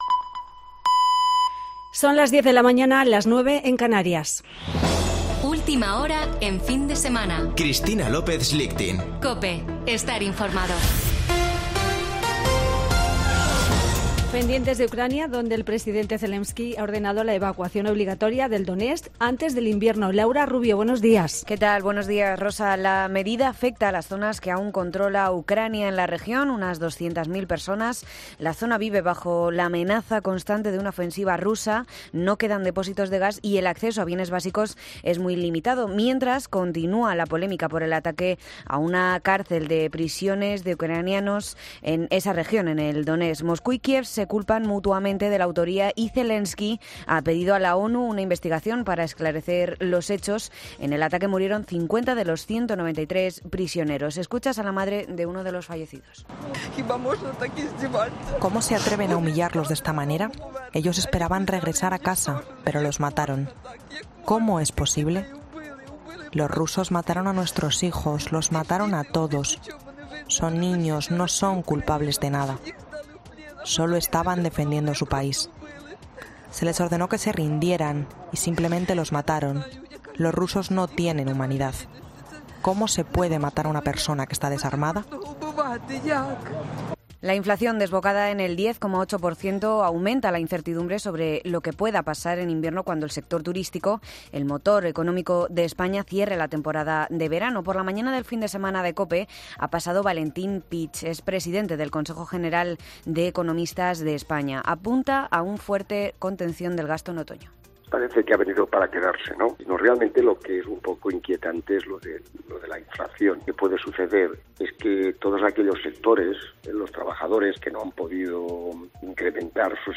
AUDIO: Boletín de noticias de COPE del 31 de julio de 2022 a las 10:00 horas